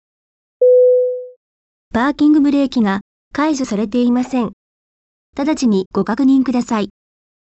音声案内　改め[音声警告システム]
パーキングブレーキ未解除警告